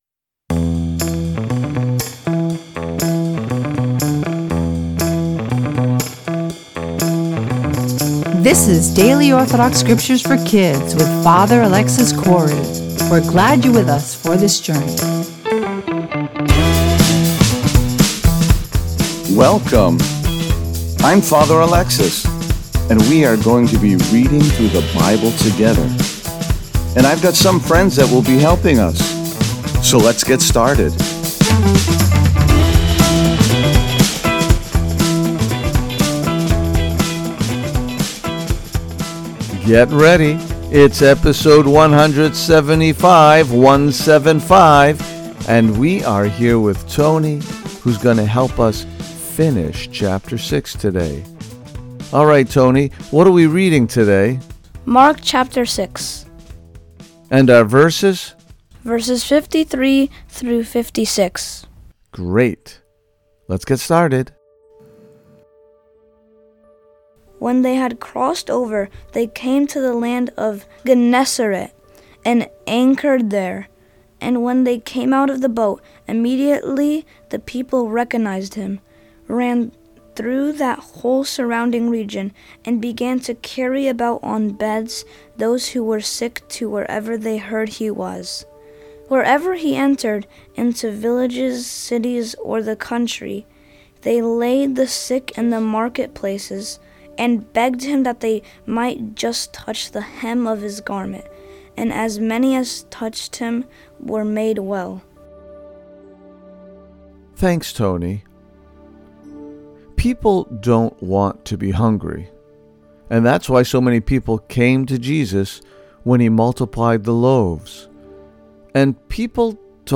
Beautiful Listening to the Children read the Holy Gospel from the Bible!